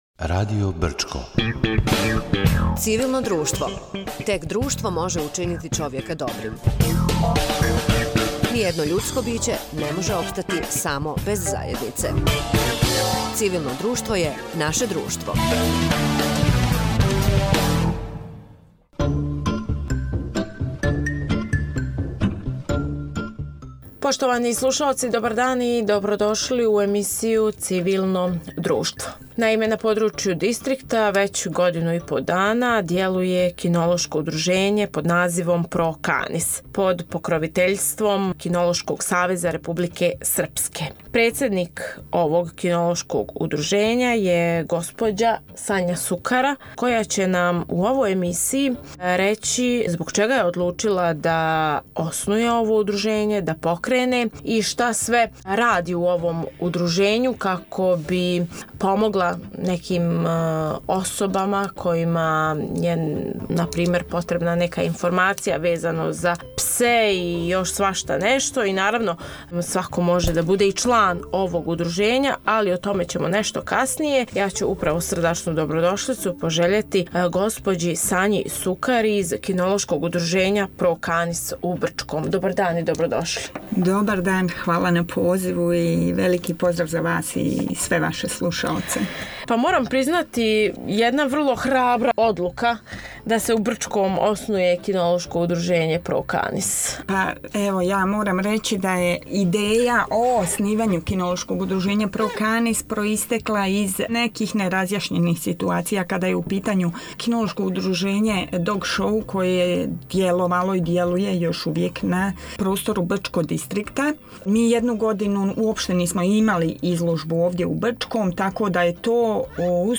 Разговарали смо о раду удружења, циљевима и плановима који су пред њима.